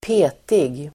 Uttal: [²p'e:tig]